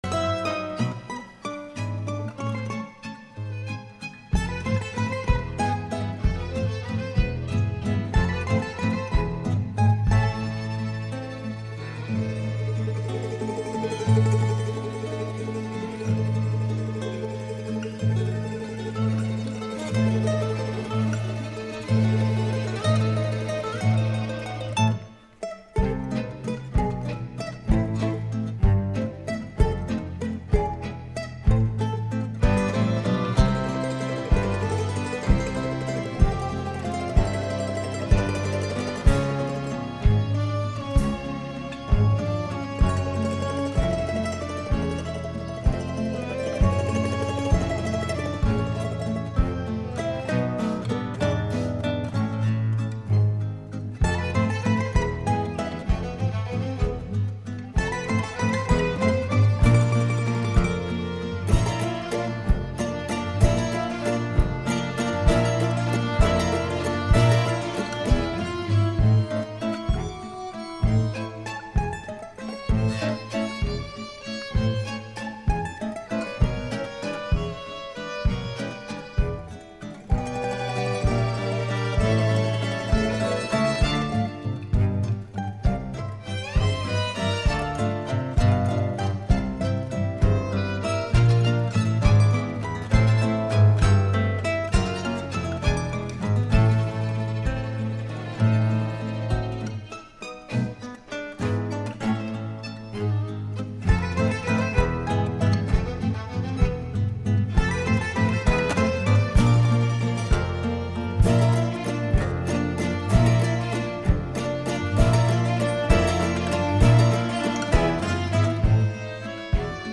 литавры, барабаны
Запись, сведение и мастеринг